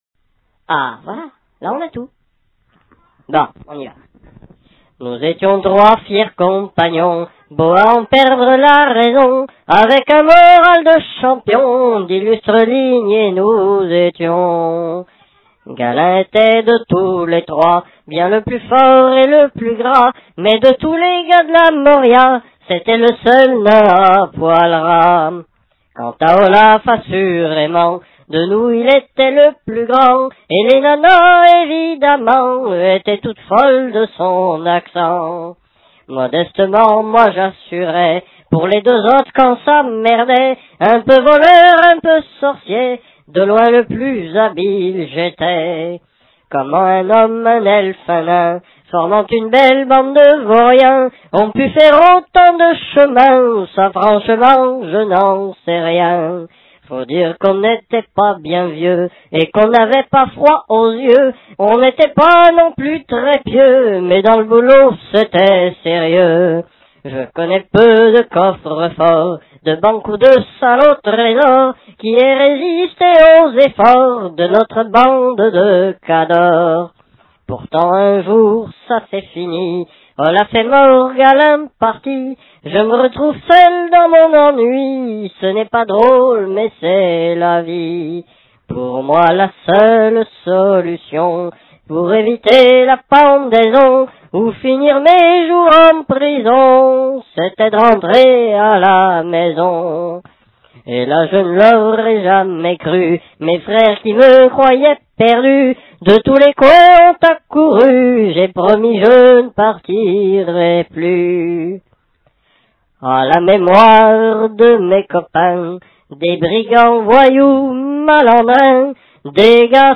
chanson d'elfe